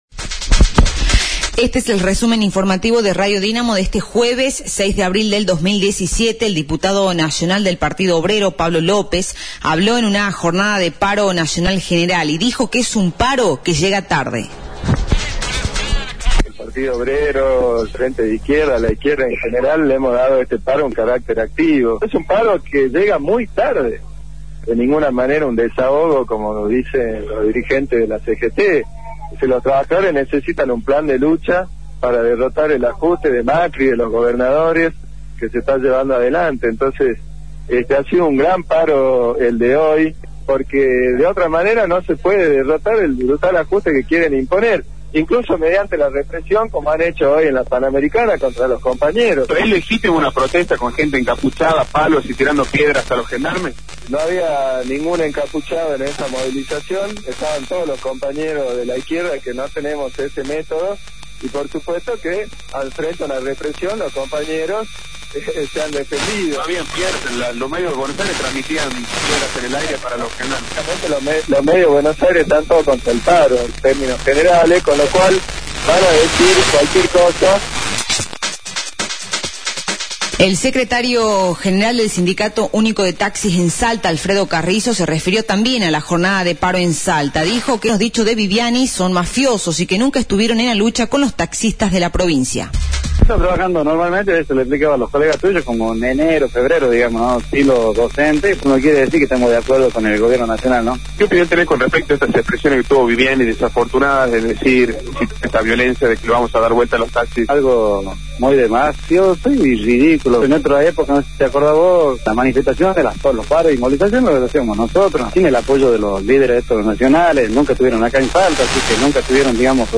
Resumen Informativo de Radio Dinamo del día 06/04/2017 1° Edición
El Diputado Nacional Pablo López habló en la jornada de paro nacional general y dijo que es un paro que llega tarde.
La Diputada Provincial, Matilde Balduzzi se refirió a la situación del Diputado Provincial Jorge Guaymas acusado de violencia de género, dijo que la defensa del mencionado legislador se la dan en la misma cámara sus pares varones.